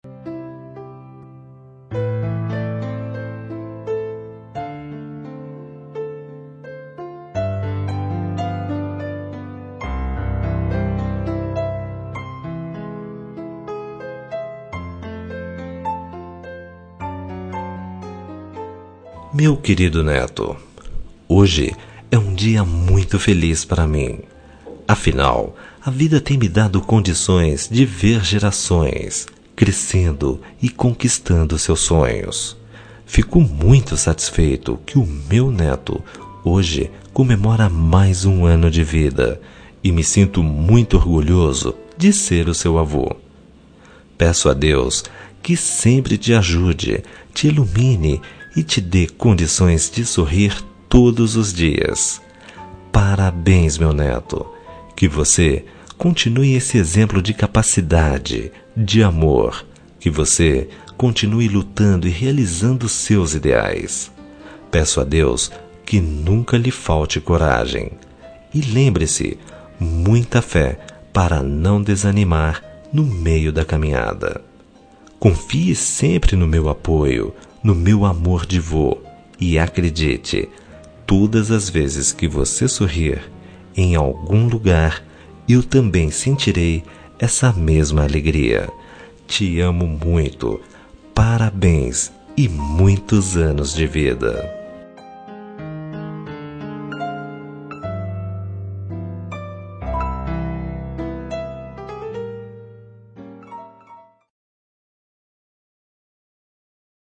Aniversário de Neto – Voz Masculina – Cód: 131060